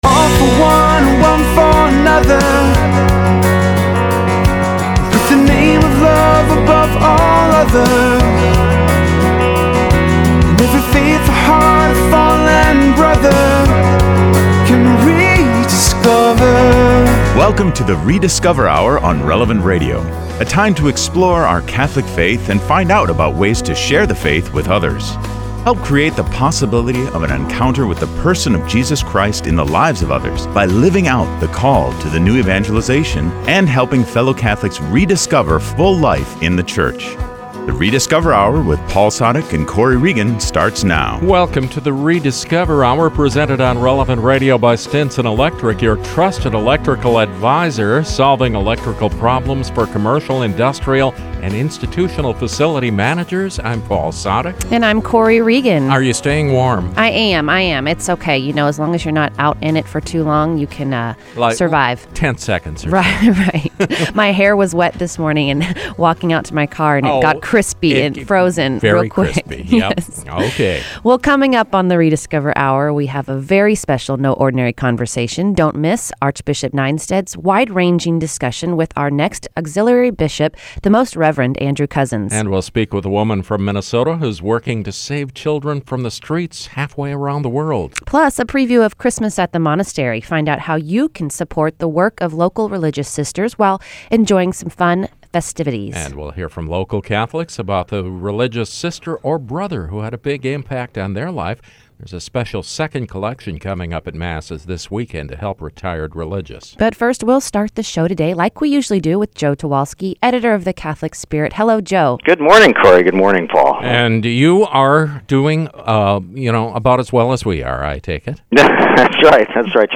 Don’t miss Archbishop Nienstedt’s wide-ranging discussion with our next auxiliary bishop, The Most Reverend Andrew Cozzens. Plus, a preview of Christmas at the Monastery.